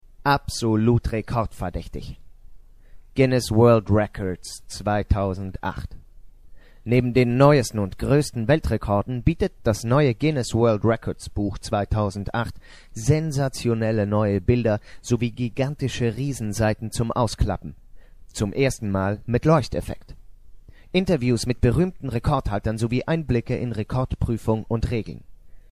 deutschsprachiger Sprecher. Sprechalter: von 16-35
Sprechprobe: Industrie (Muttersprache):